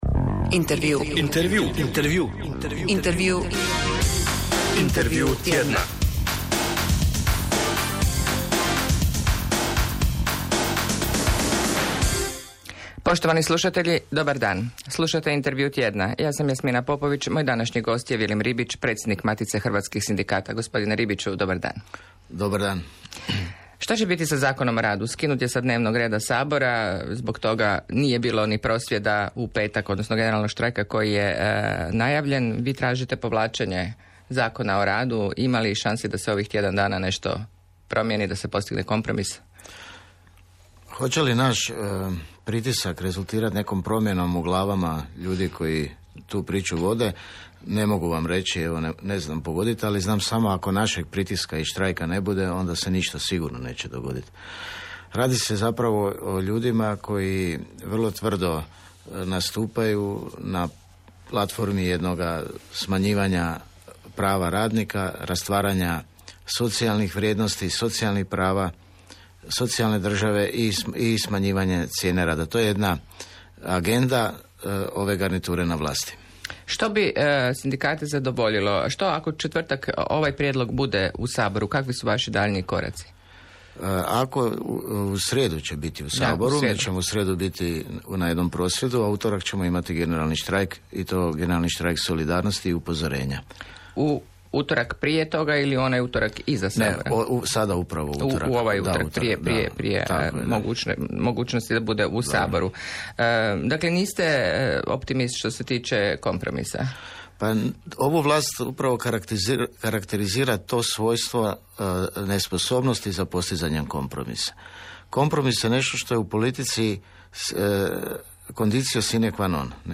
U subotnjoj polusatnoj emisiji na Hrvatskom radiju gostovao je